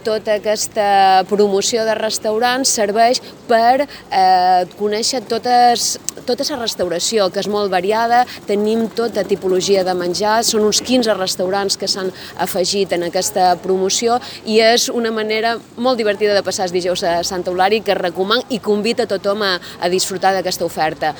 Corte de voz Carmen Ferrer